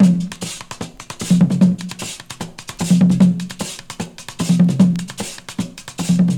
Original creative-commons licensed sounds for DJ's and music producers, recorded with high quality studio microphones.
150 Bpm Modern Breakbeat Sample F Key.wav
Free drum groove - kick tuned to the F note. Loudest frequency: 447Hz
150-bpm-modern-breakbeat-sample-f-key-e1T.wav